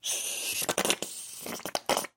Звуки клизмы
Звук применения медицинской клизмы